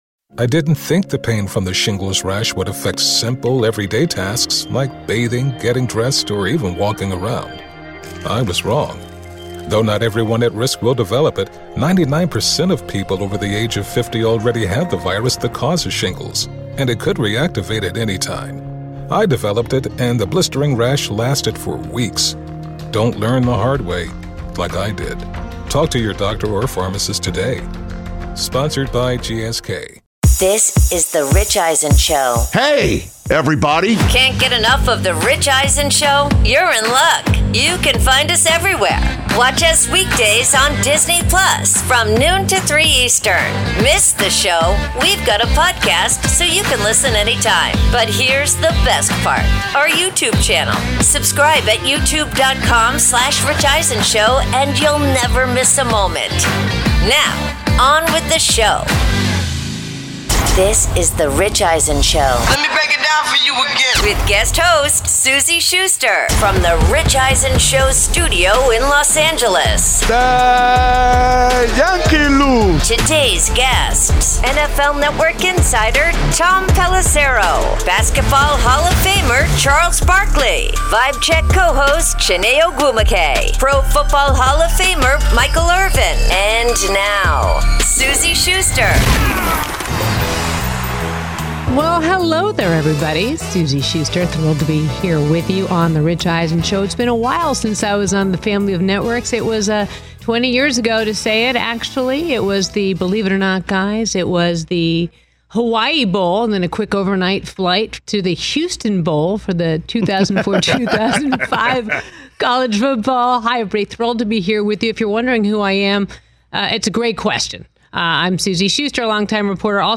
Hour 1: Guest Host Suzy Shuster, NFL Insider Tom Pelissero, plus Eagles/Giants Preview
Guest host Suzy Shuster and the guys preview the Philadelphia Eagles vs New York Giants on Thursday Night Football to kick off NFL Week 6.